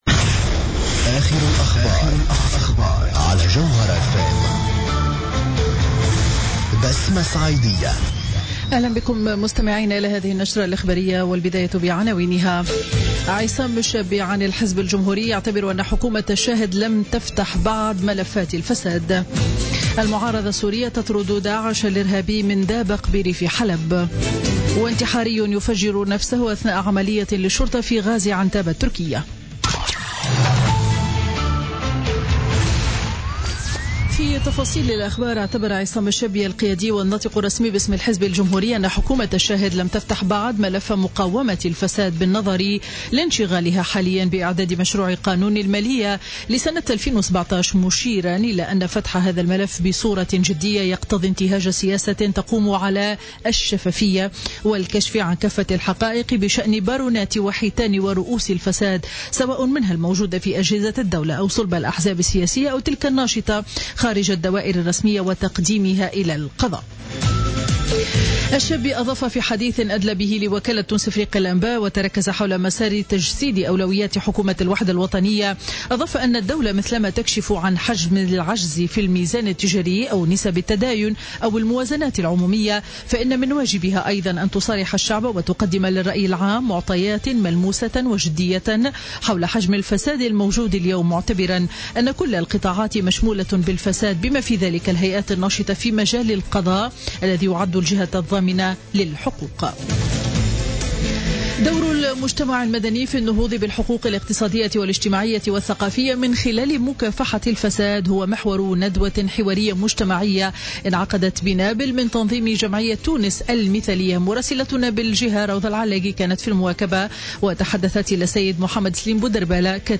نشرة أخبار منتصف النهار ليوم الأحد 16 أكتوبر 2016